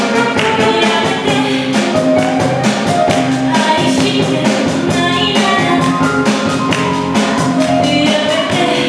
丸亀ユニオン’９９コンサート・第１部
今回は、辺見マリの代表曲「経験」を歌ってもらった。